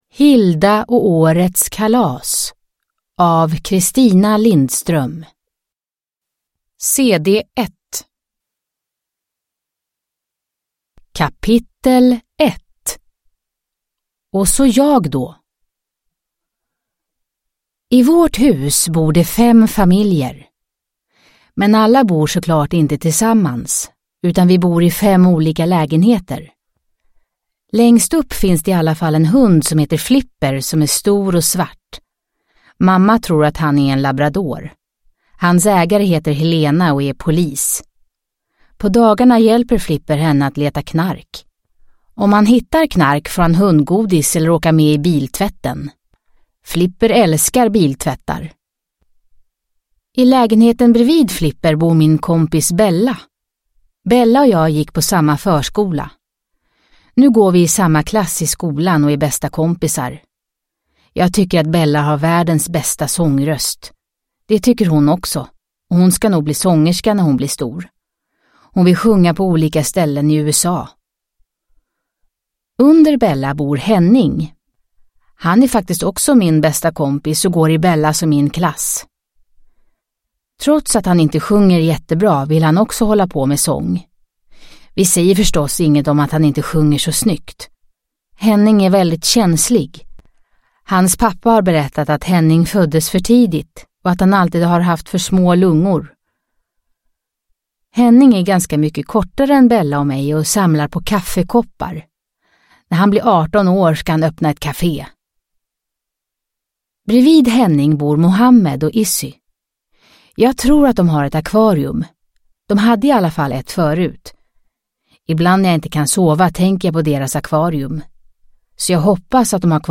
Hilda och årets kalas (ljudbok) av Christina Lindström